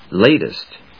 音節lat・est 発音記号・読み方
/léɪṭɪst(米国英語), ˈleɪt.ɪst(英国英語)/